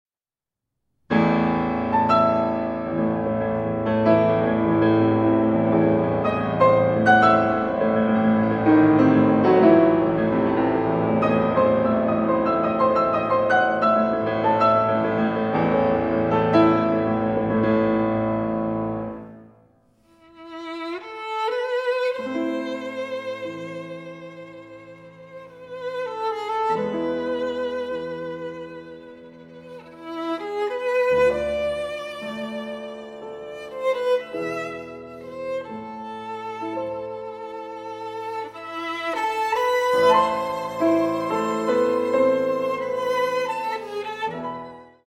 for violin and piano
violin
piano